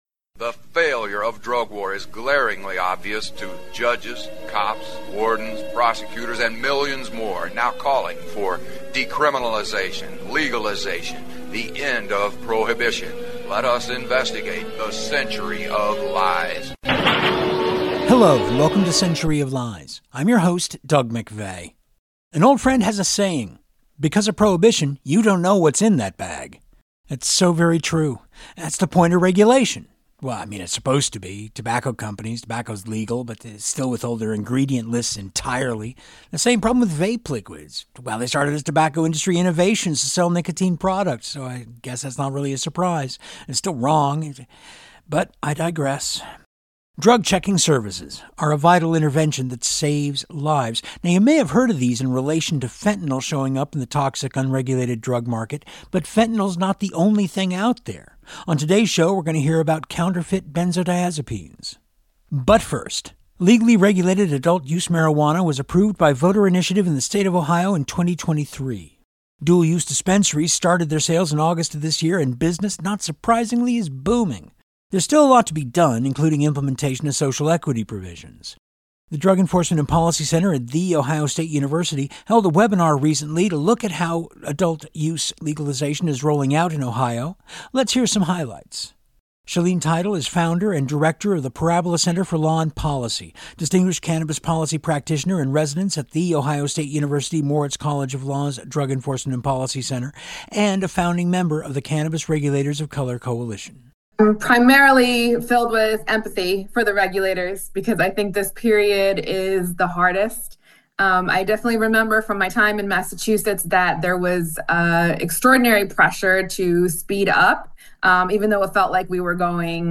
We'll hear portions of that presentation on today's show.